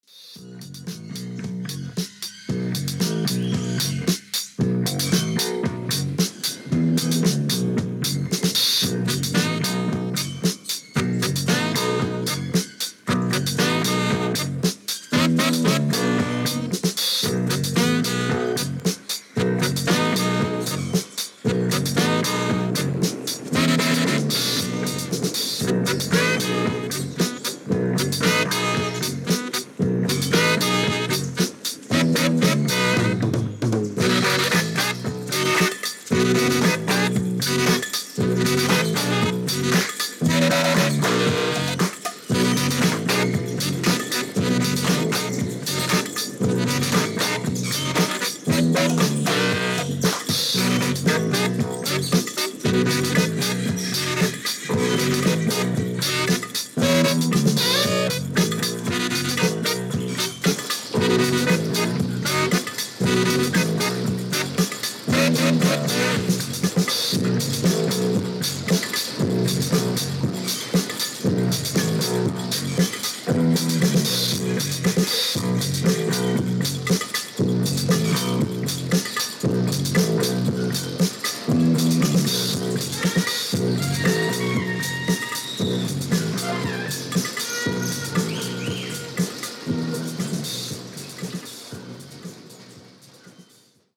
Vocals, Piano